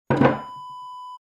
Wooden Chair Set Down Wav Sound Effect #5
Description: The sound of setting down a wooden chair on the floor
Properties: 48.000 kHz 16-bit Stereo
A beep sound is embedded in the audio preview file but it is not present in the high resolution downloadable wav file.
wooden-chair-set-down-preview-5.mp3